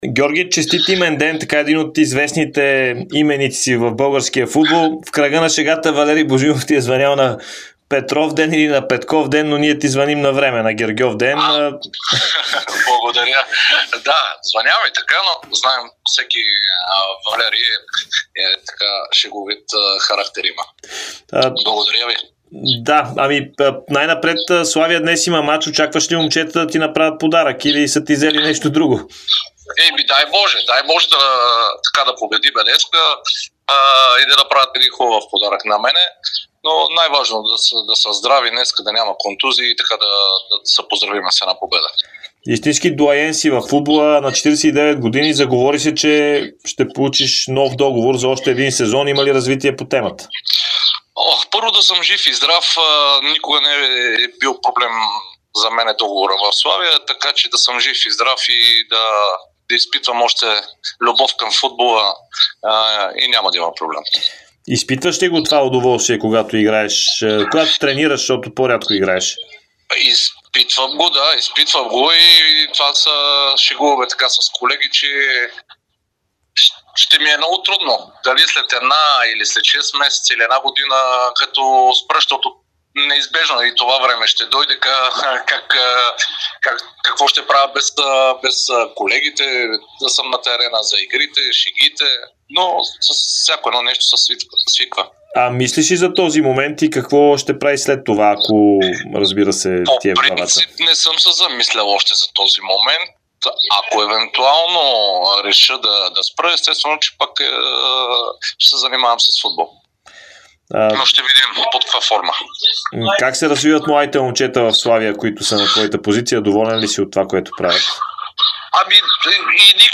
Неостаряващият Георги Петков даде ексклузивно интервю пред Дарик радио и Dsport навръх своя имен ден. В него той говори по различни теми, свързани със себе си и със Славия.